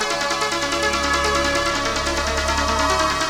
DISCO LOO01R.wav